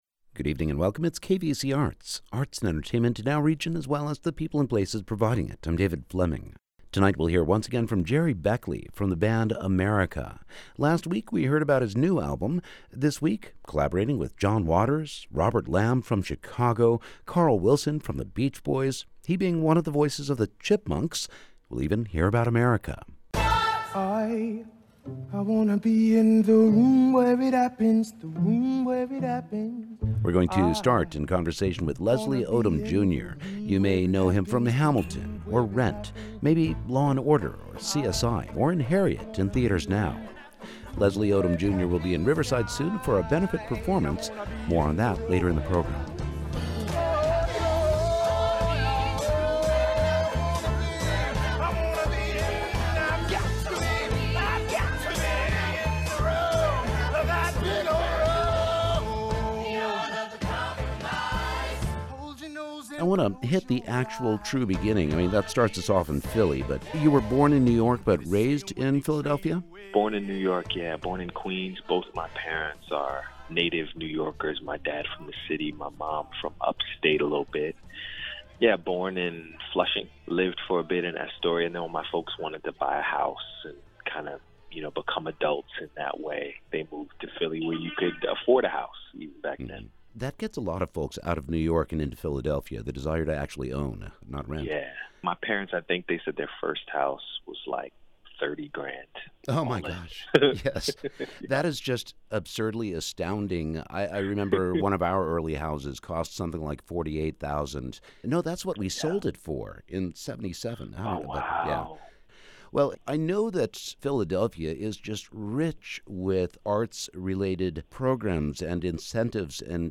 Interviews with authors, producers, visual artists and musicians, spotlighting a classic album or a brand new release.